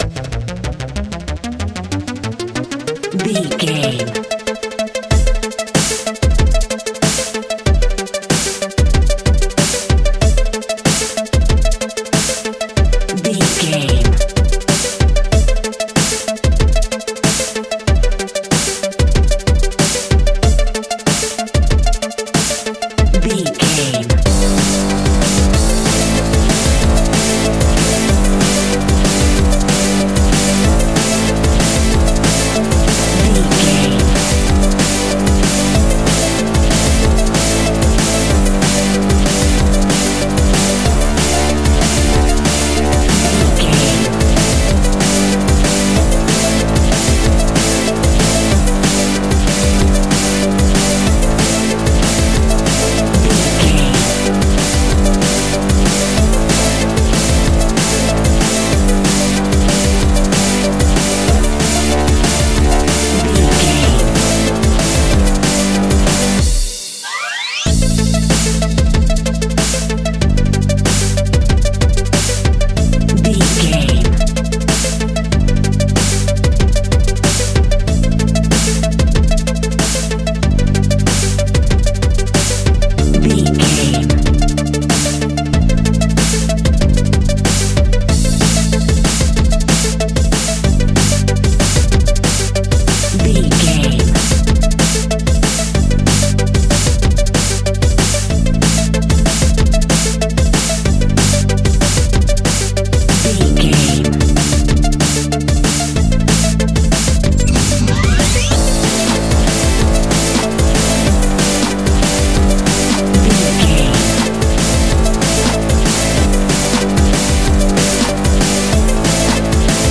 Fast paced
Aeolian/Minor
intense
futuristic
energetic
driving
dark
aggressive
drum machine
electronic
sub bass
Neurofunk
synth leads
synth bass